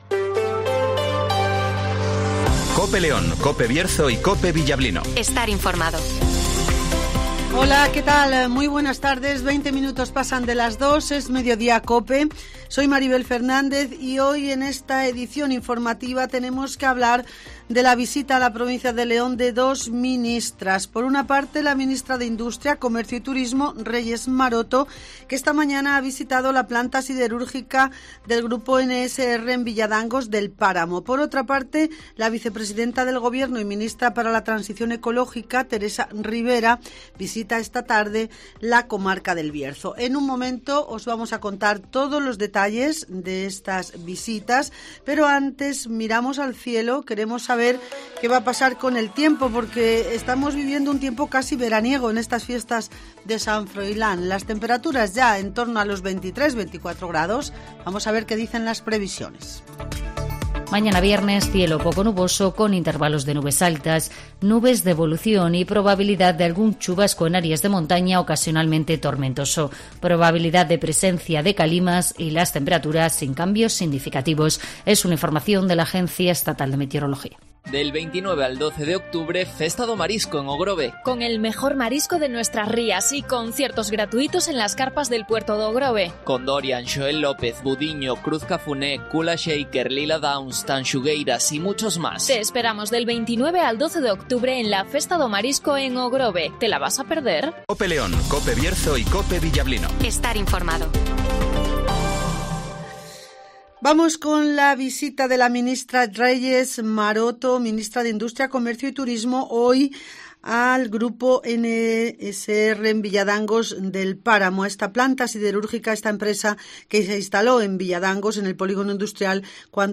- Reyes Maroto ( Ministra de Industria, Comercio y Turismo )